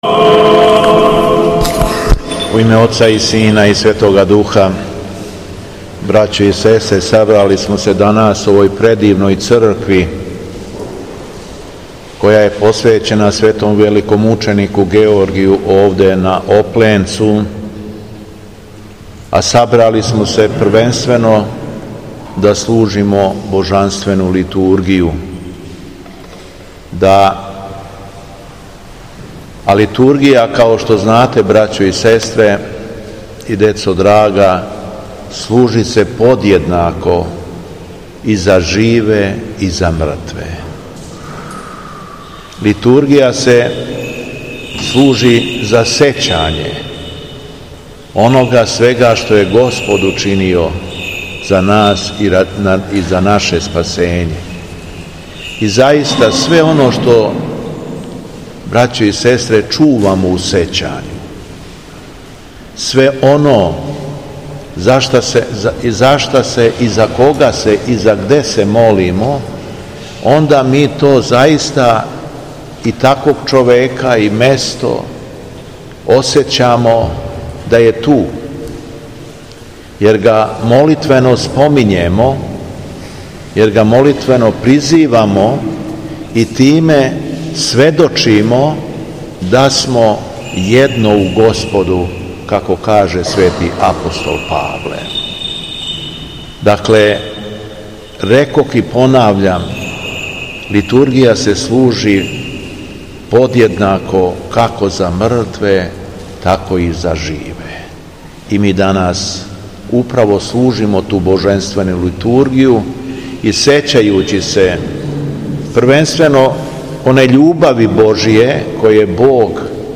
У понедељак, 9. октобра 2023. године, на дан када се у Марсеју 1934. године догодио атентат на Краља Александра Карађорђевића, Његово Преосвештенство Епископ шумадијски Господин Јован служио је Свету Литургију у храму Светог великомученика Георгија на Опленцу, задужбини краља Петра I Карађорђевића....
Беседа Његовог Преосвештенства Епископа шумадијског г. Јована
Након прочитаног Јеванђеља, Епископ Јован је рекао: